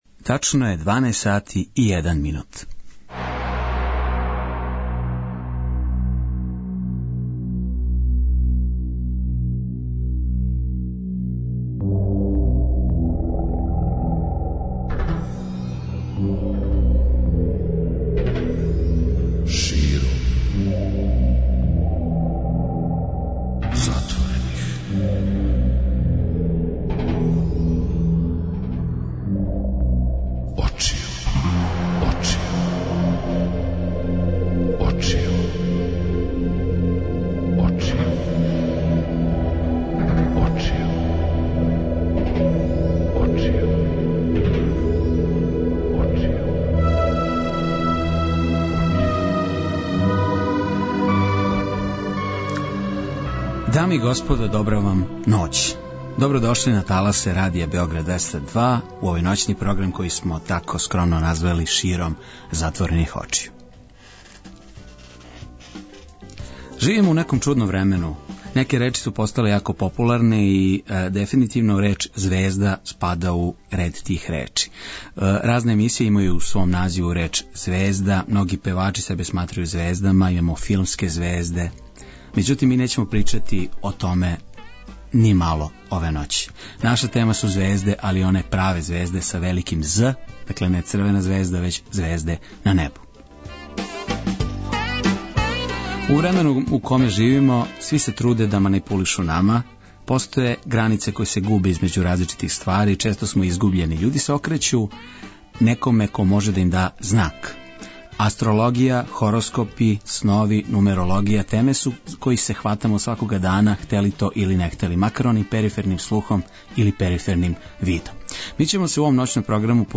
Да бисмо што боље упознали основне појмове астрологије, издраду наталних карти, астролошке куће и наравно хороскоп, дружимо се са два занимљива госта.